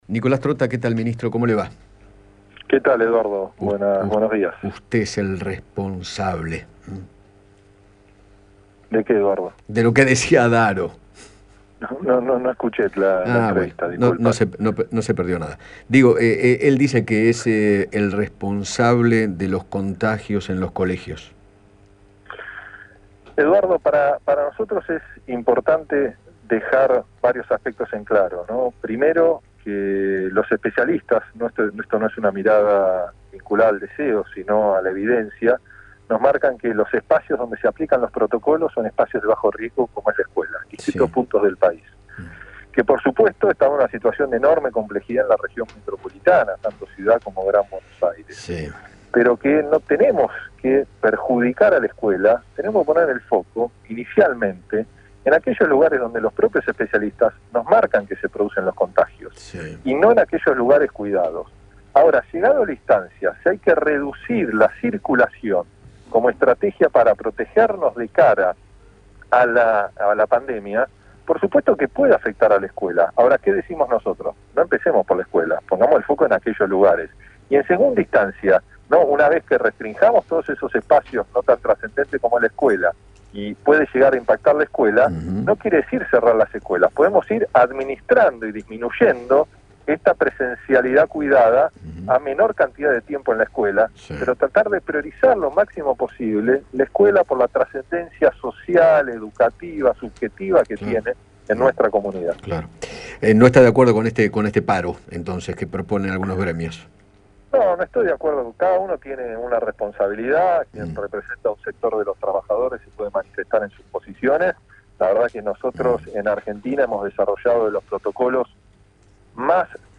Nicolás Trotta, ministro de Educación de la Nación, dialogó con Eduardo Feinmann sobre  la presencialidad en las aulas, el poco riesgo de contagio que implican e hizo hincapié en “poner el foco en aquellos lugares donde los especialistas nos dicen que se dan más contagios”.